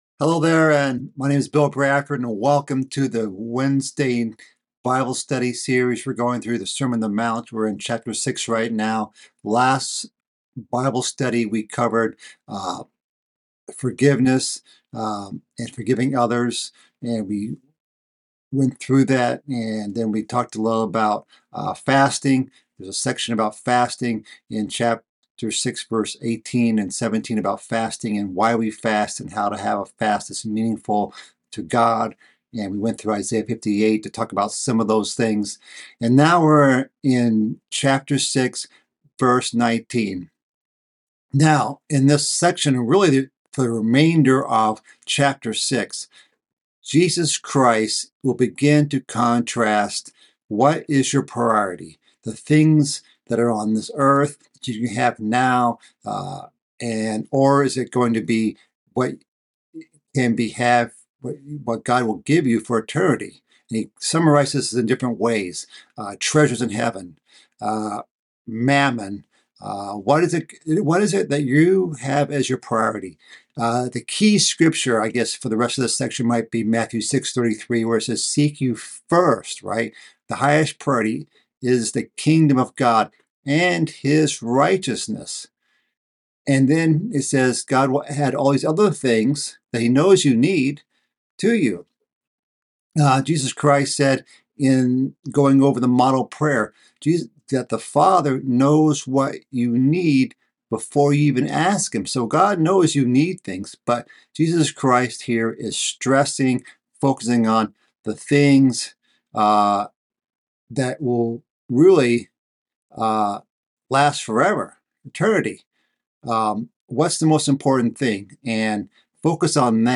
This is part of a mid-week Bible study series about the sermon on the mount. This part is about laying up for ourselves treasures in heaven.